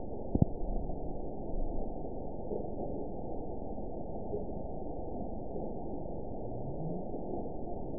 event 922622 date 02/09/25 time 23:44:25 GMT (2 months, 3 weeks ago) score 9.16 location TSS-AB04 detected by nrw target species NRW annotations +NRW Spectrogram: Frequency (kHz) vs. Time (s) audio not available .wav